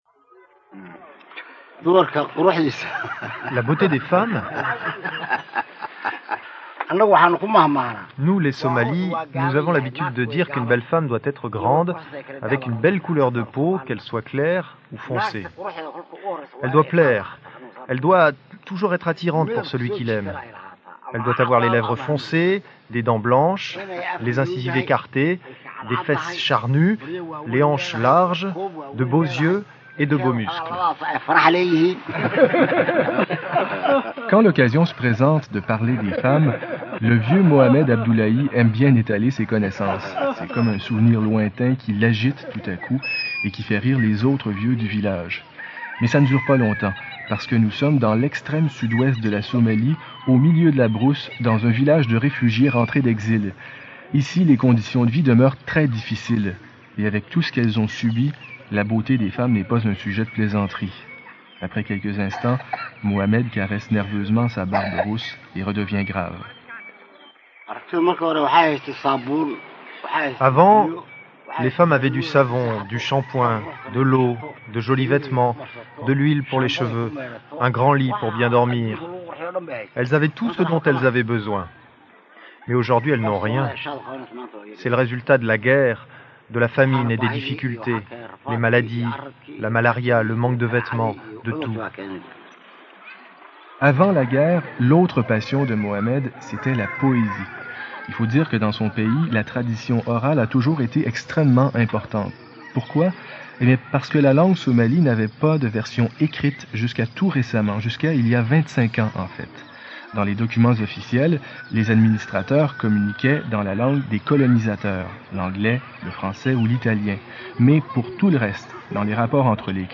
*Première diffusion (Radio-Canada): décembre 1995